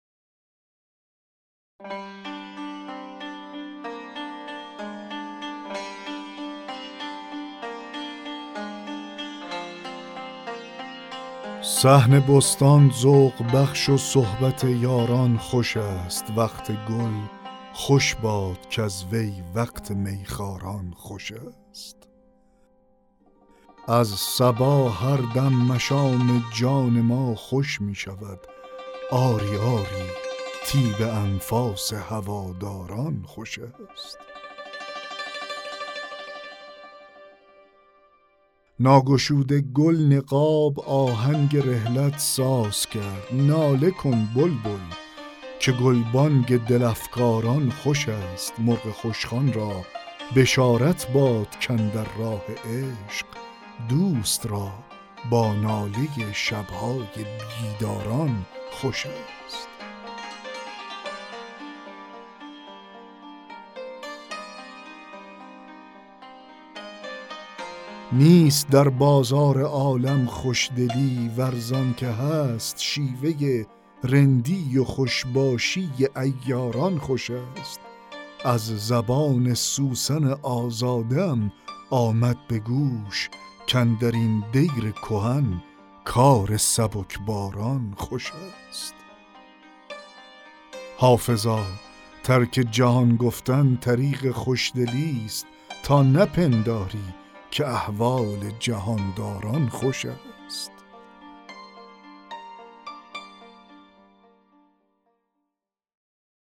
دکلمه غزل 43 حافظ